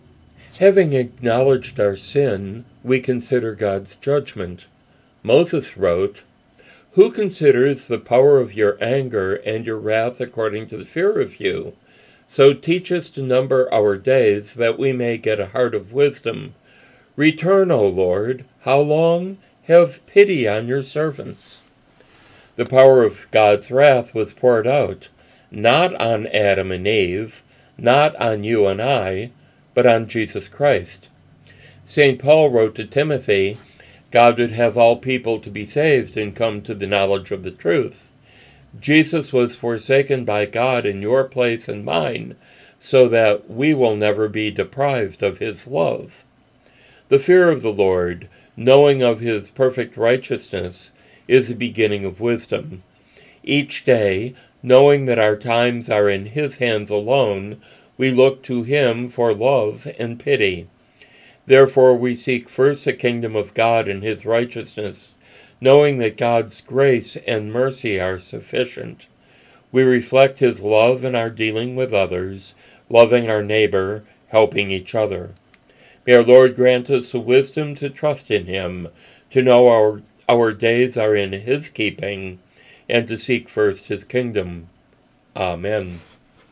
Broadcast